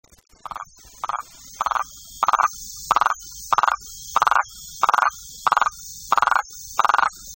chant: sorte de roulement grave et prolongé:
chant margaritifer.mp3